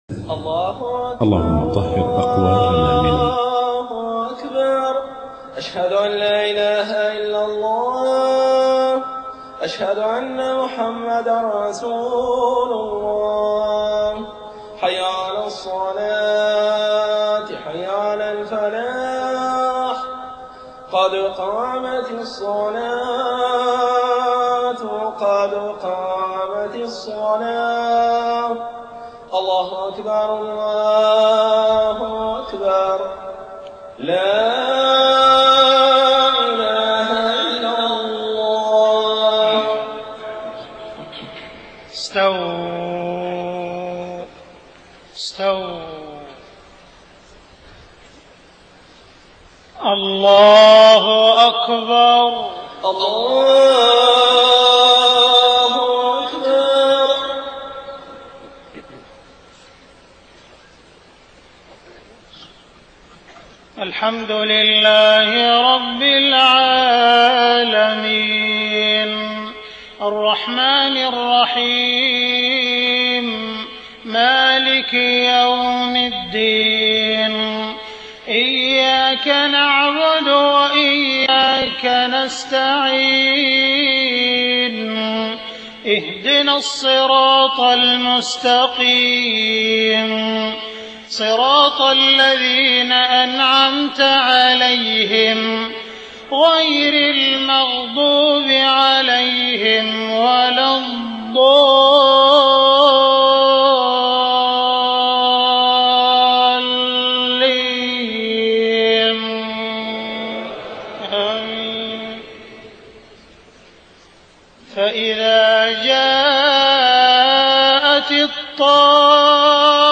صلاة المغرب 3 محرم 1430هـ خواتيم سورتي النازعات 34-46 وعبس 33-42 > 1430 🕋 > الفروض - تلاوات الحرمين